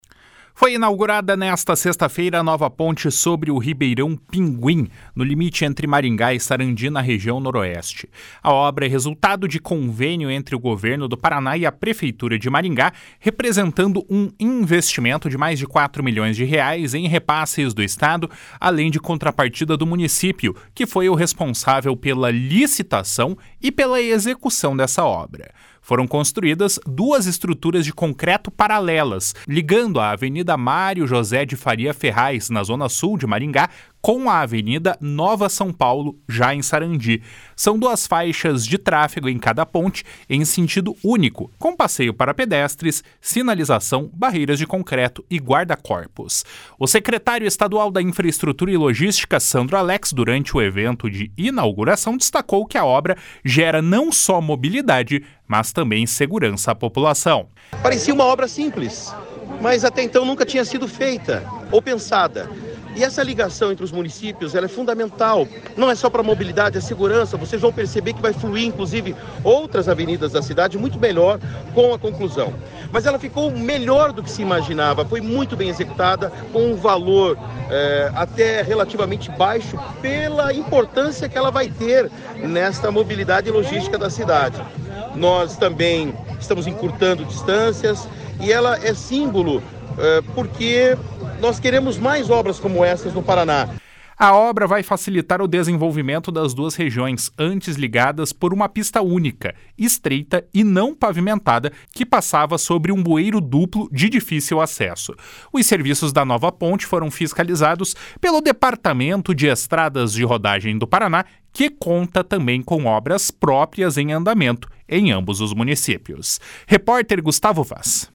O secretário estadual de Infraestrutura e Logística, Sandro Alex, durante o evento de inauguração, destacou que a obra gera não só mobilidade, mas também segurança .// SONORA SANDRO ALEX //